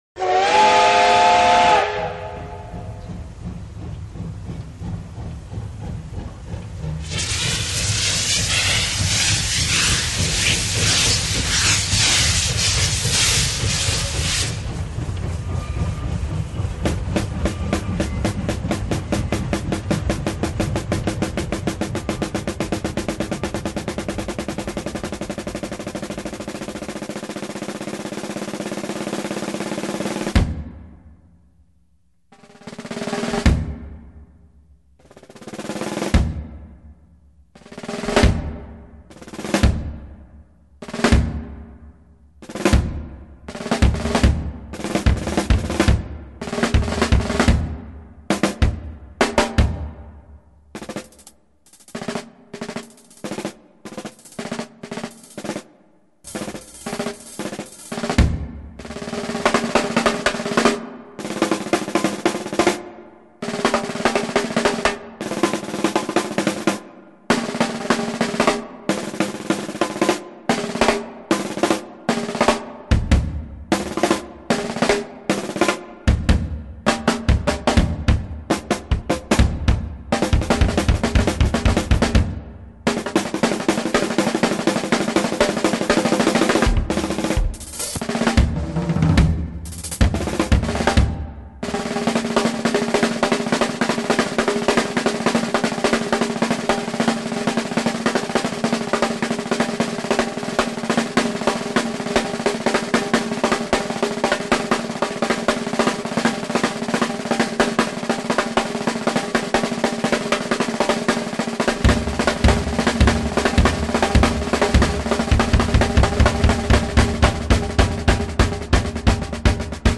美丽而怀旧，